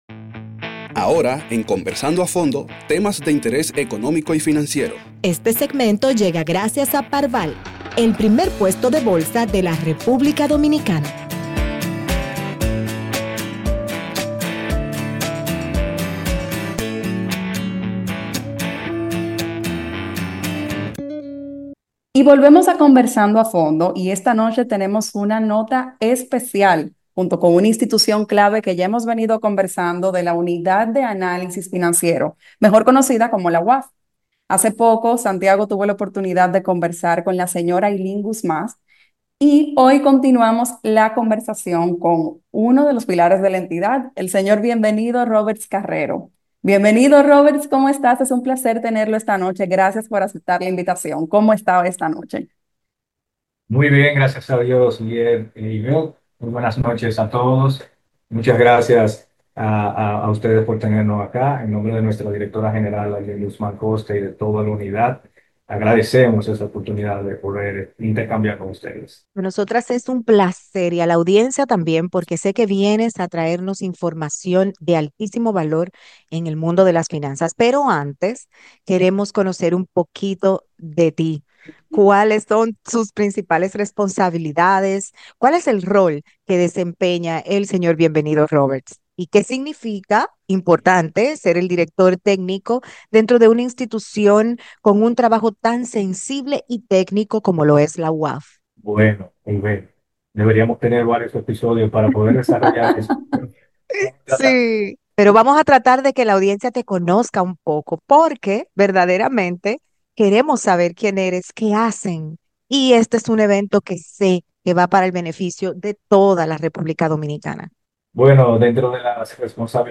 conversa con nosotros sobre el rol de la institucion y detalles sobre el 8vo congreso internacional contra el lavado de activos.